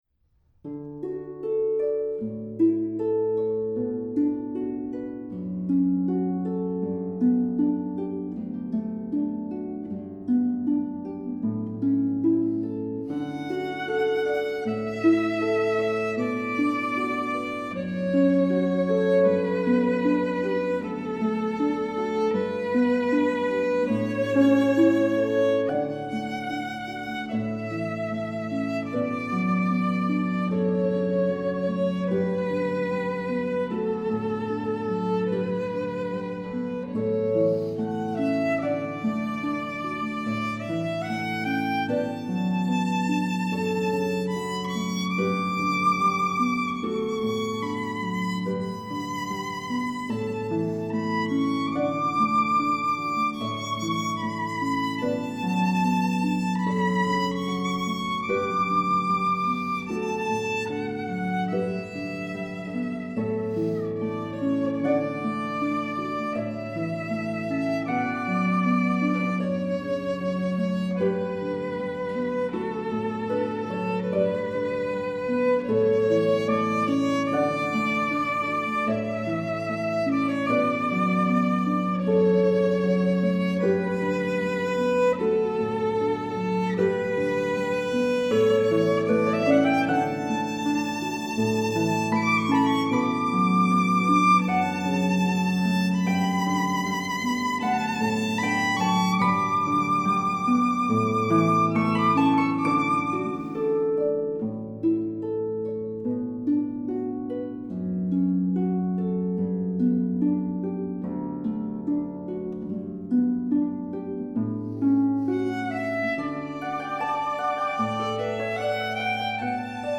traditional English carol
for flute or violin, and lever or pedal harp.